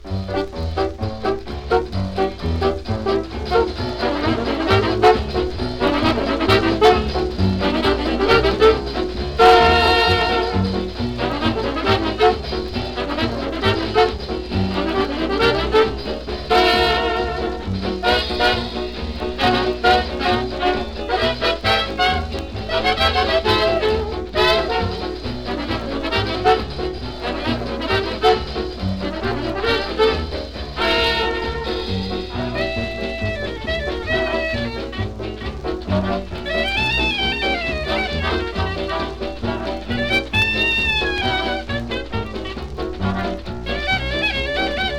Jazz, Big Band　UK　12inchレコード　33rpm　Mono